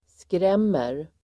Uttal: [skr'em:er]